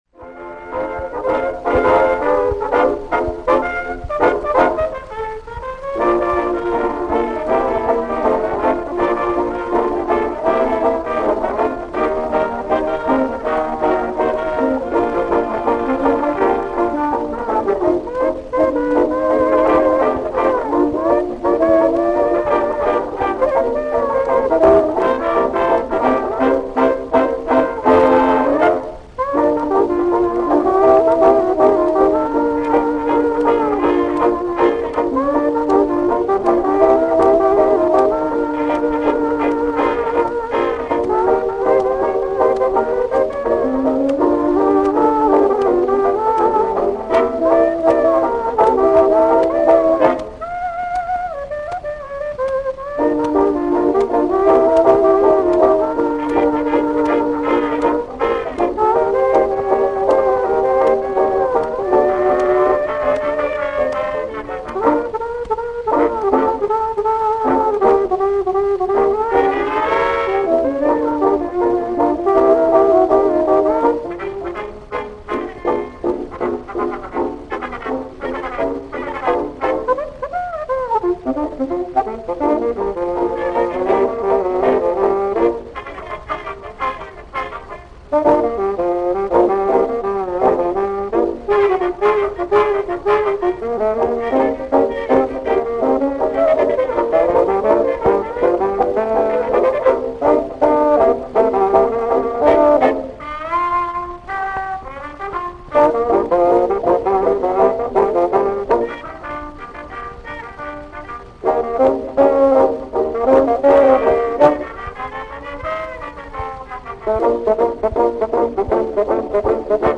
an instrumental version